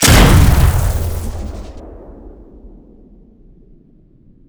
fire1.wav